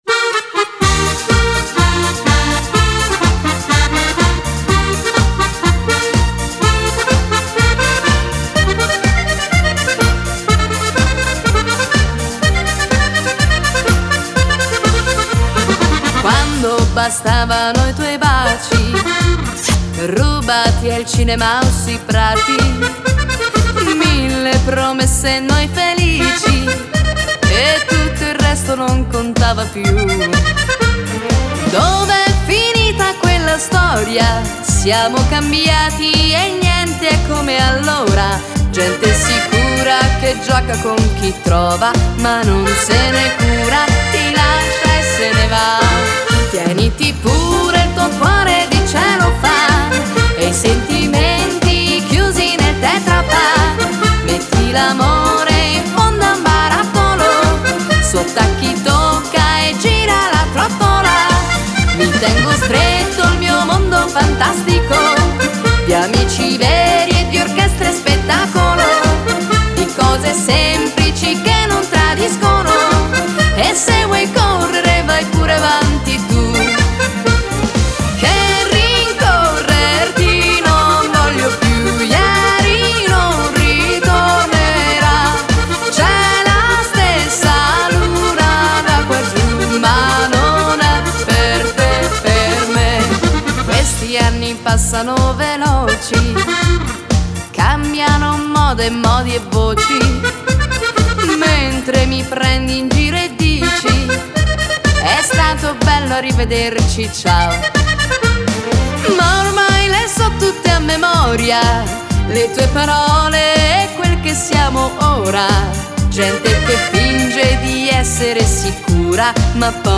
Ritmo allegro / Passeggiata